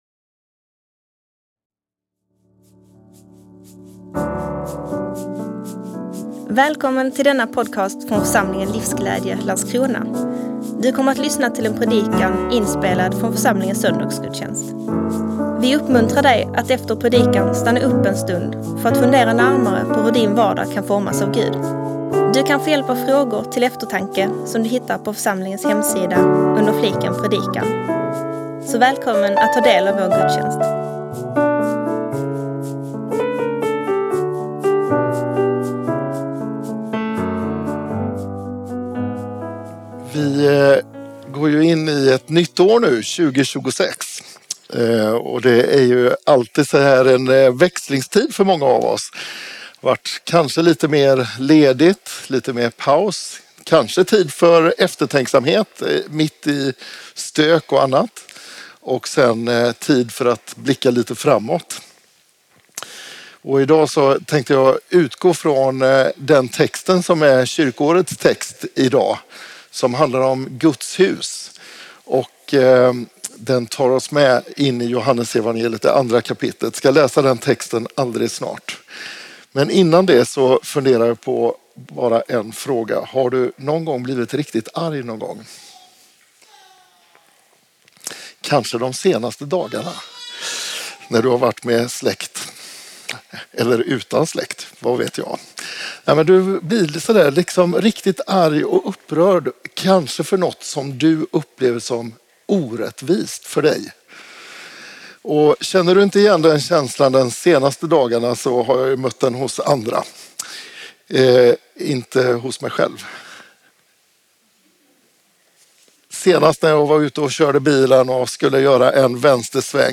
Årets första gudstjänst.